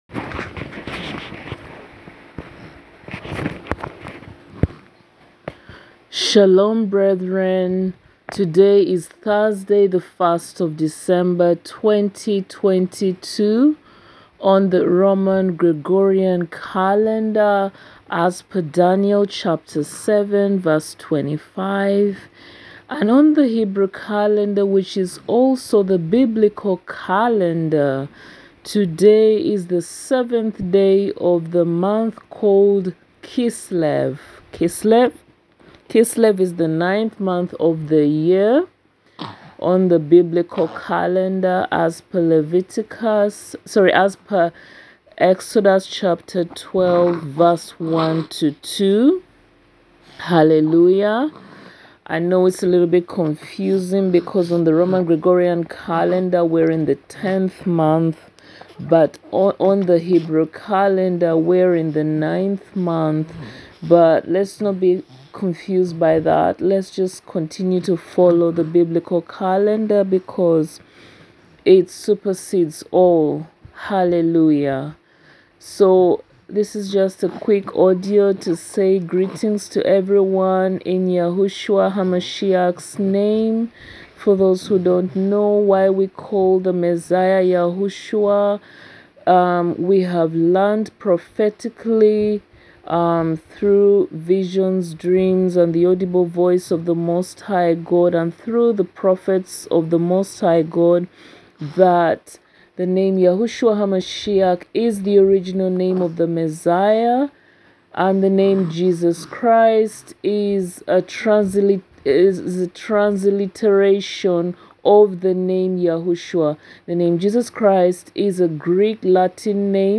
It’s SHABBAT VAYISHLACH/AND HE SENT [Saturday 10th December 2022/KISLEV 16] Prophetic Teaching!